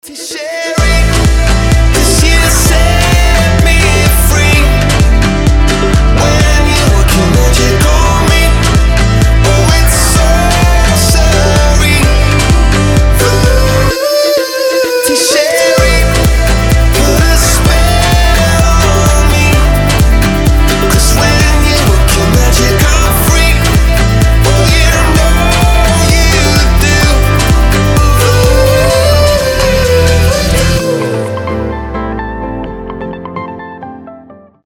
• Качество: 320, Stereo
поп
мотивирующие
заводные
вдохновляющие
воодушевляющие